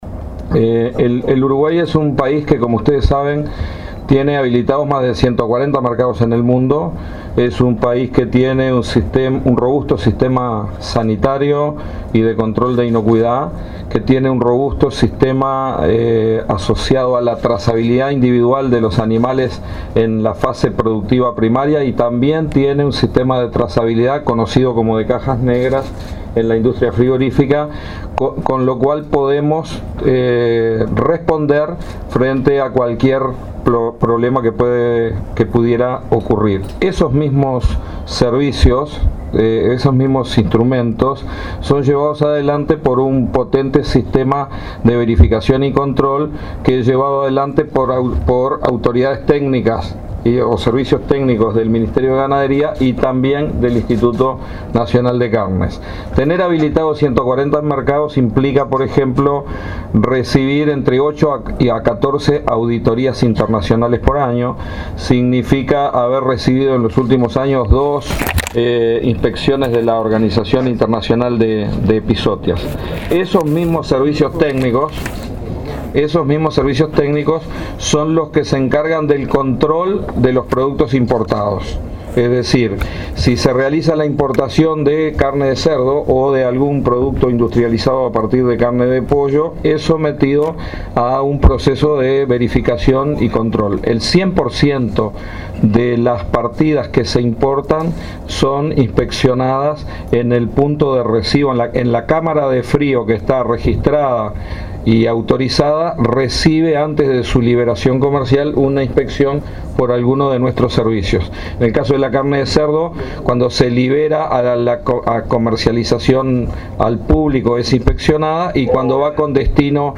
El Uruguay tiene un "potente" sistema de control de los productos que se importan y el principio que rige a nivel internacional es el de sancionar a las plantas y no a los países, afirmó el ministro de Ganadería, Tabarè Aguerre, tras la denuncias por carne adulterada en Brasil. "No ha habido riesgo para la población uruguaya", dijo en su mensaje.